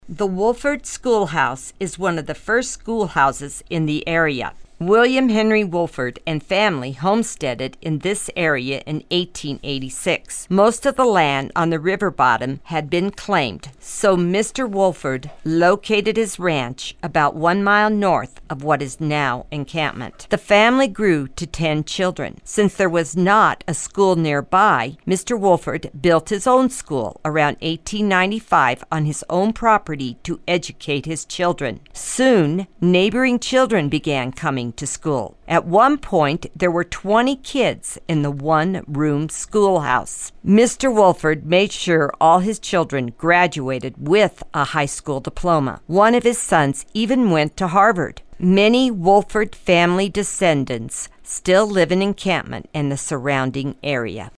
Audio Tour